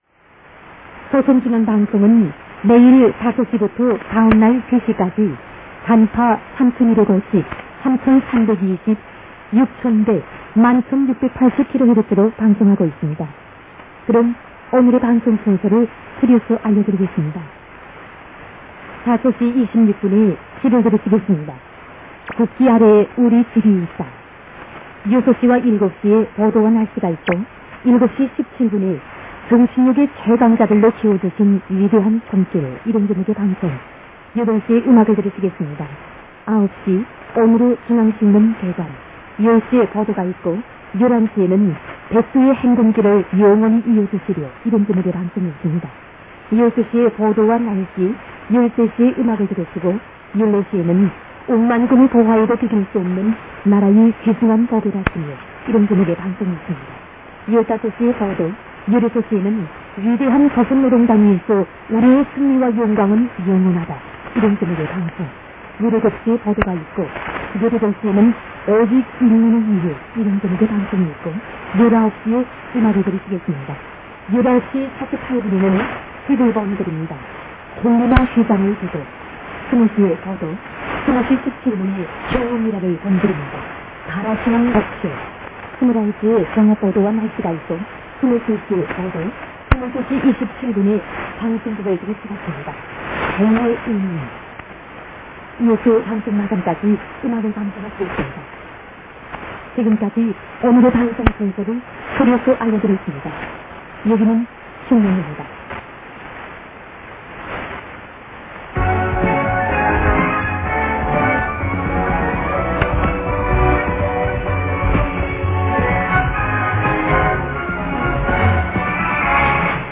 ・00:00-07:30 0505’30“-0513’00“ 放送時間、周波数、今日の番組紹介。 その後、歌。
<受信地：東京都江東区新砂 東京湾荒川河口 RX:ICF-SW7600GR ANT:AN-12>
※00:05-00:12 女性周波数アナウンス「短波 三千二百五十(samcheon-ibaeg-osib 3250)，三千九百六十(samcheon-ibaeg-osib 3960)，六千百(yugcheonbaeg 6100)，万千六百八十(mancheon-yugbaegpalsib 11680)kHz」
11680kHzは最近ブツブツといったノイズのような音が混じるので少々聞き難いのですが、09日朝の受信ではそれがなかったのでラッキーでした。